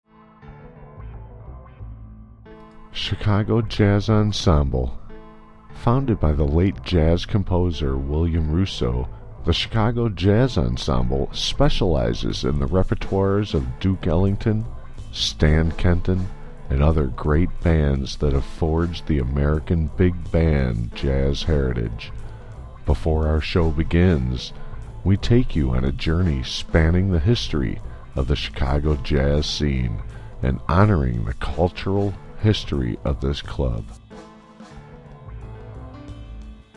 Straight Focus Design near Chicago offers voice over work and a voice artist
Chicago Jazz Demo - > :36 seconds